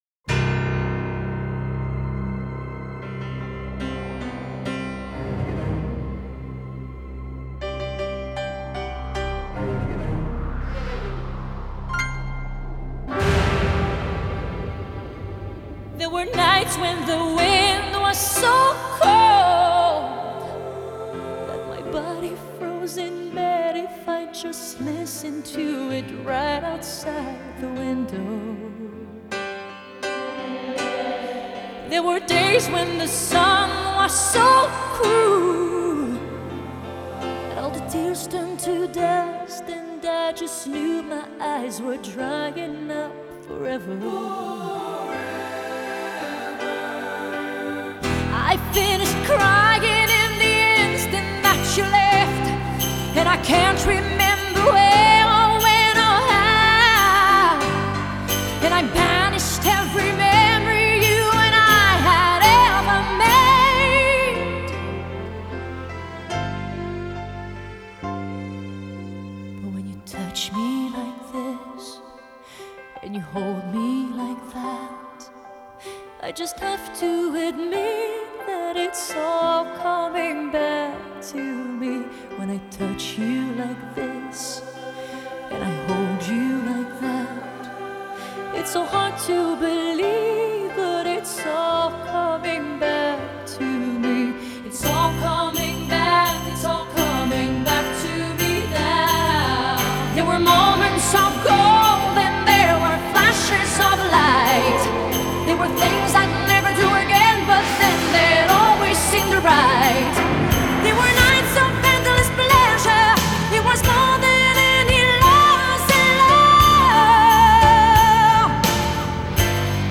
Pop, Pop Rock, Ballad, Adult Contemporary